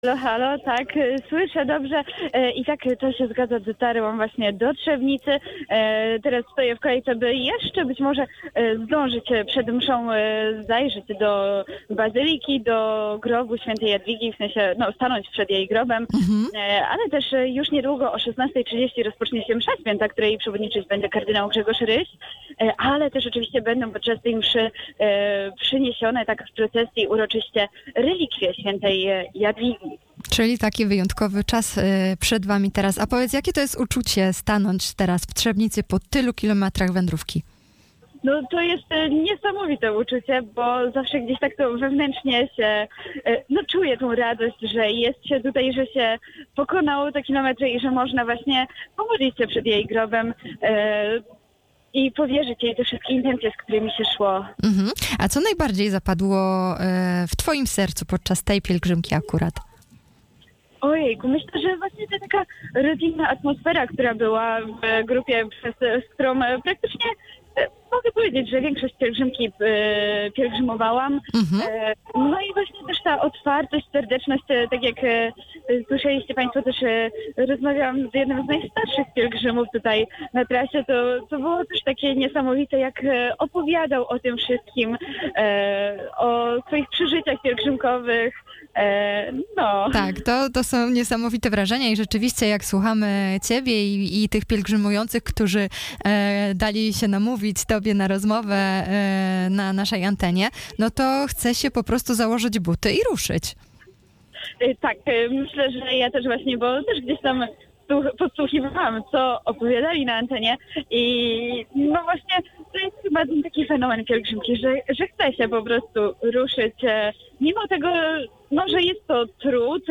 rozmowa-na-antenie-PIELGRZYMKA-3.mp3